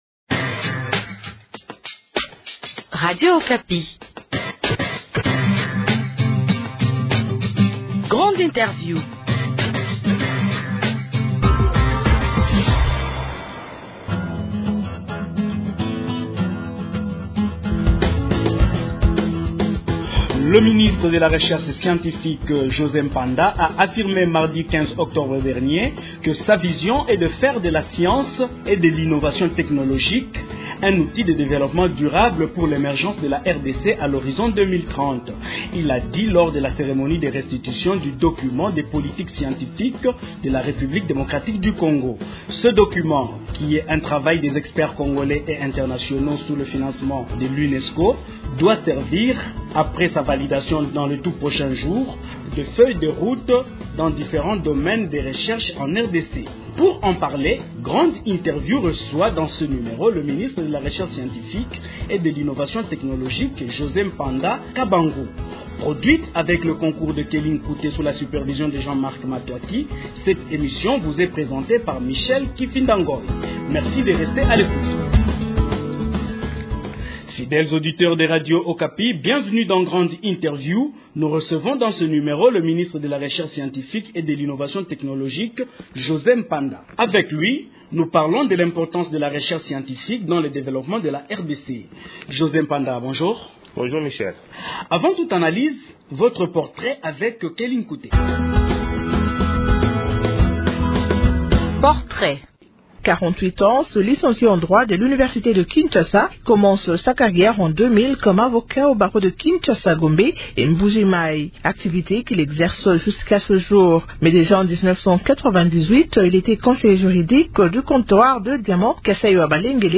Le ministre de la Recherche scientifique et de l’Innovation technologique, José Mpanda Kabangu, est l’invité de l’émission Grande Interview de ce samedi 19 octobre.